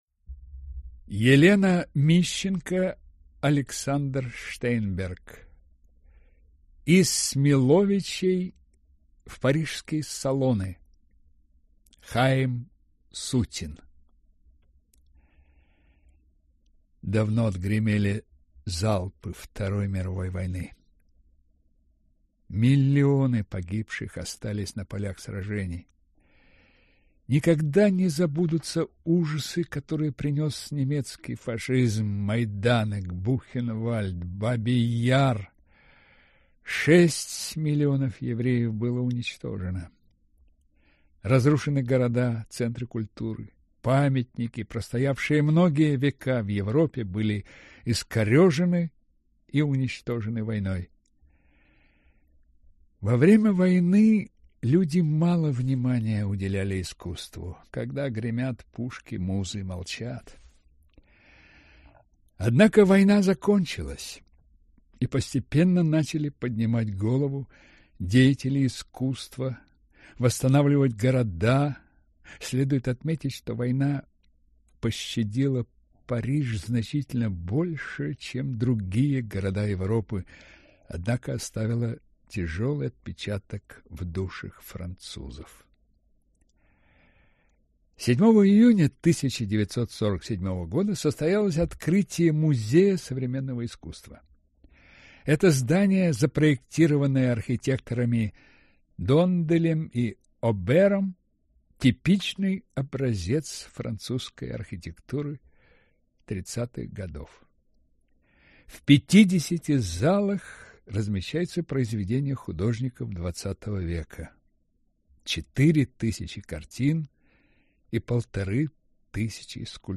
Аудиокнига Из Смиловичей в парижские салоны. Хаим Сутин | Библиотека аудиокниг